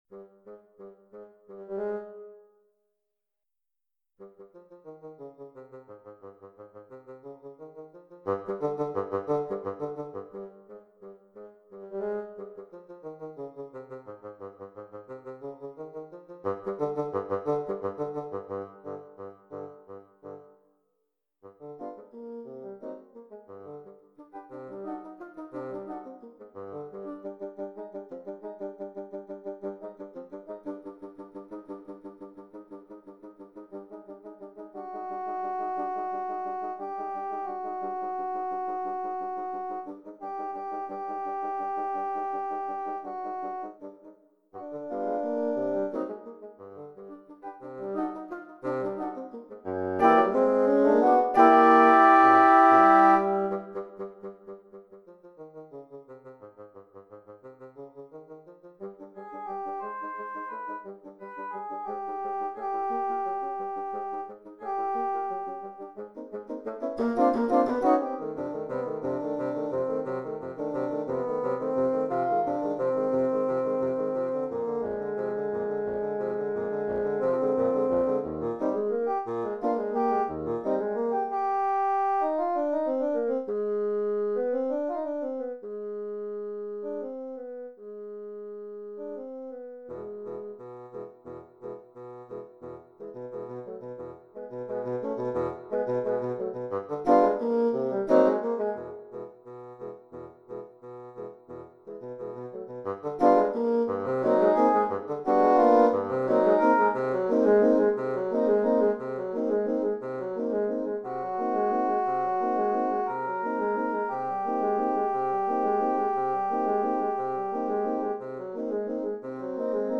Bassoon Quartet Edition